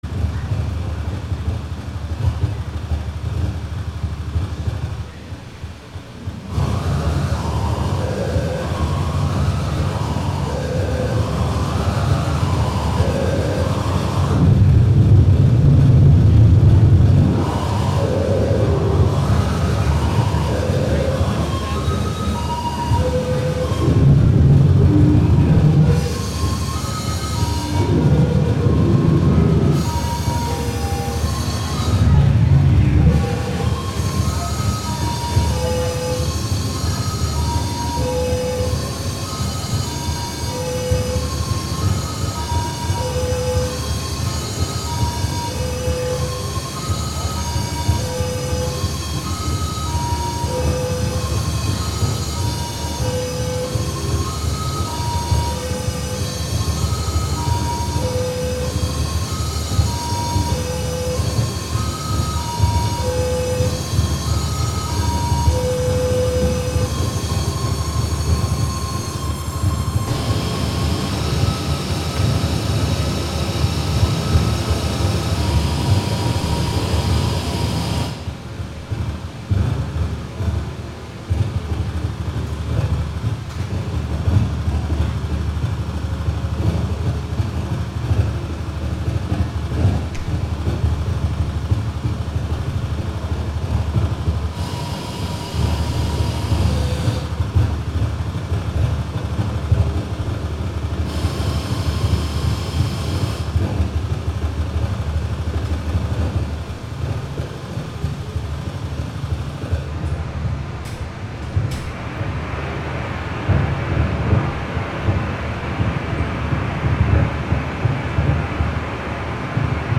Live from BuddyFest: Buddyfest (Audio)